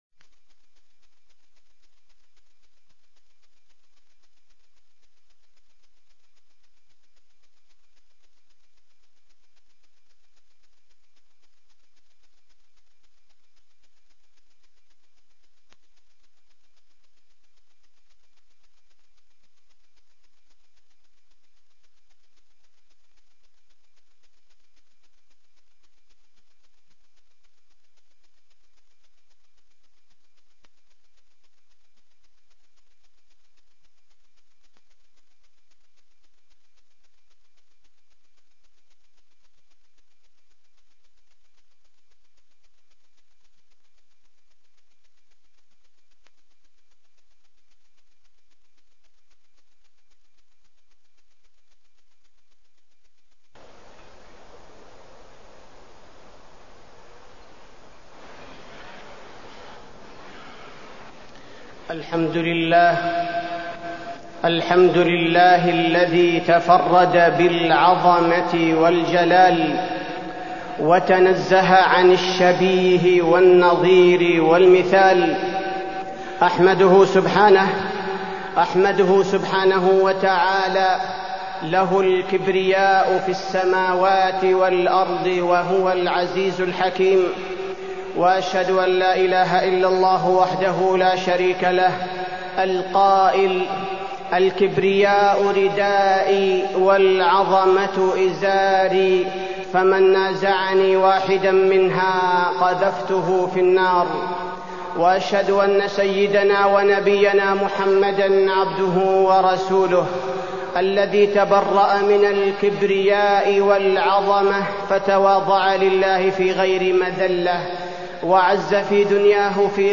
تاريخ النشر ٩ صفر ١٤٢٤ هـ المكان: المسجد النبوي الشيخ: فضيلة الشيخ عبدالباري الثبيتي فضيلة الشيخ عبدالباري الثبيتي مقومات النصر